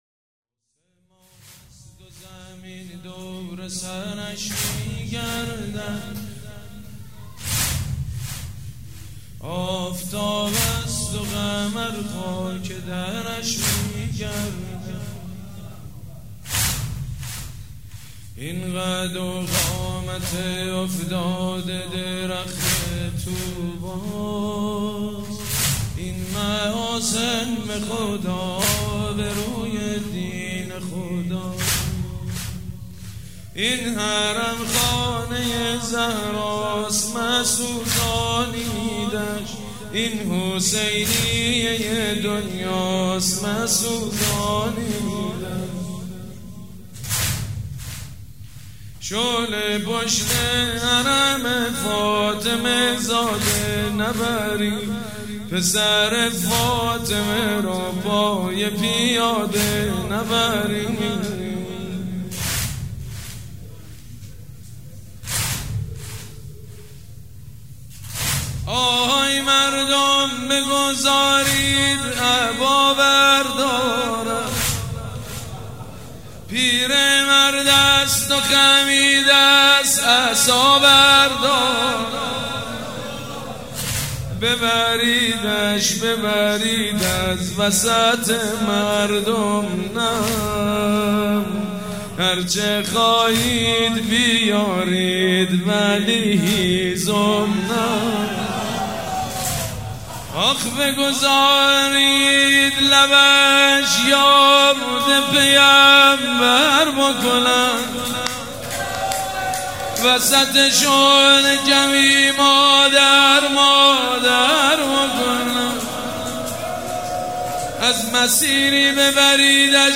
شب شهادت امام صادق علیه السلام
حسینیه ی ریحانه الحسین
مداح
حاج سید مجید بنی فاطمه